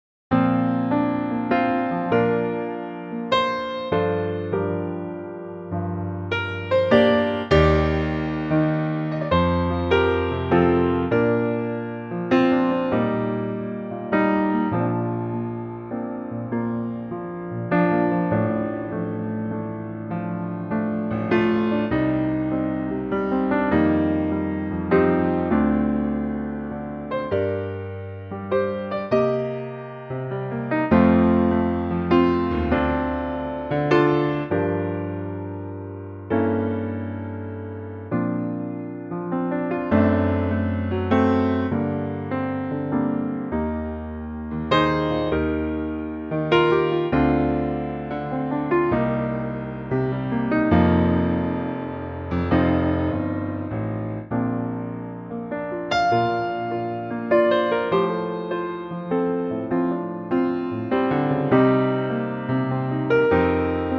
key - Bb to C - vocal range - Bb to E
Gorgeous piano only arrangement
-Unique Backing Track Downloads